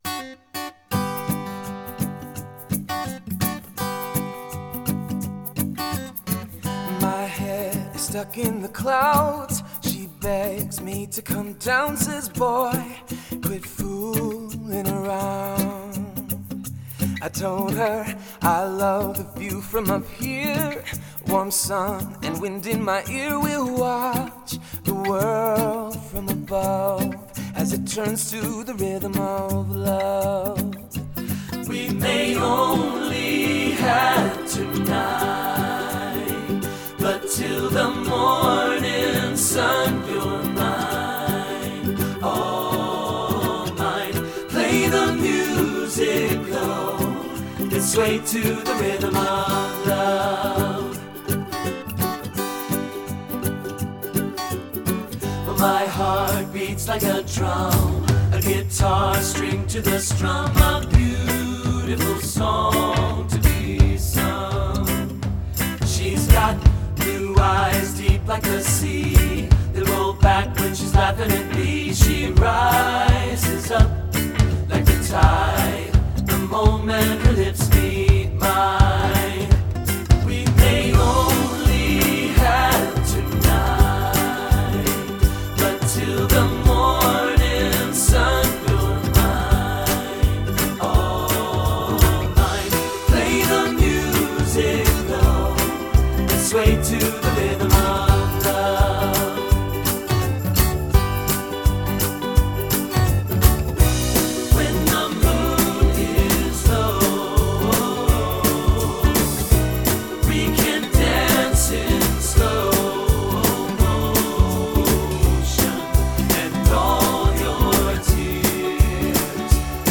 Voicing: TTB